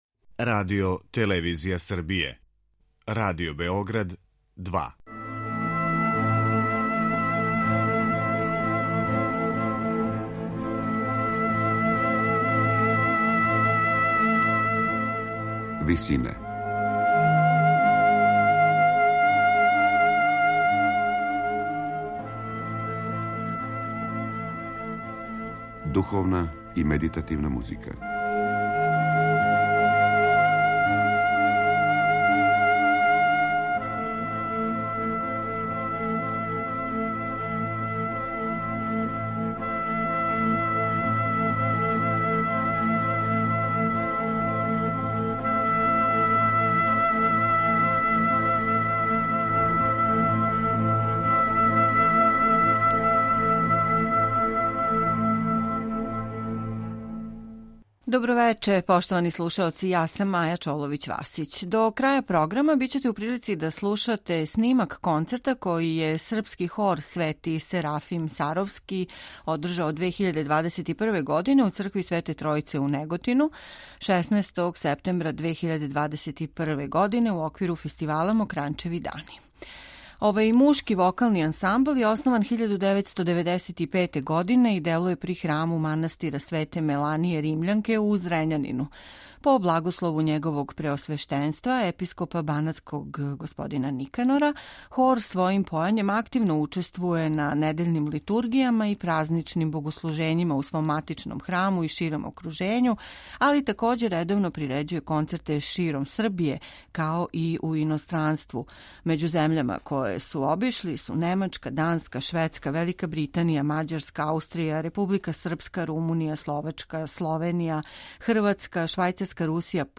тенори
баритон
басови